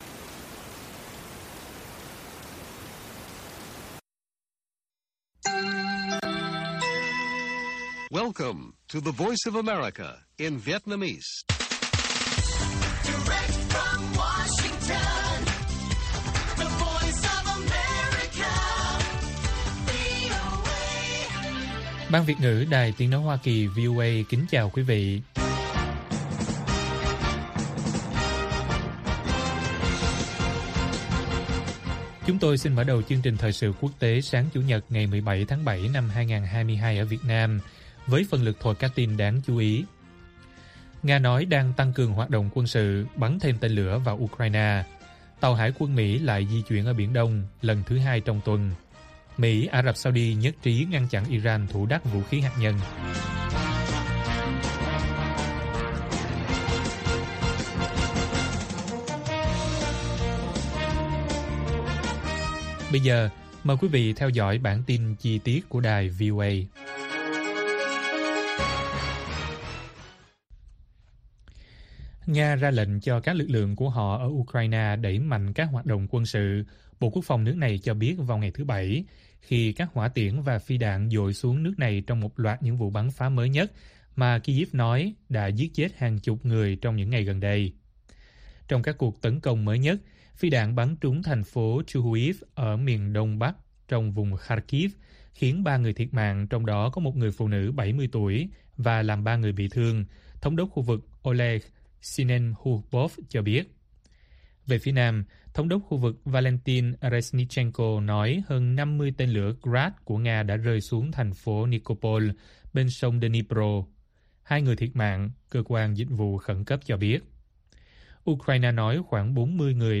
Nga nói đang tăng cường hoạt động quân sự ở Ukraine - Bản tin VOA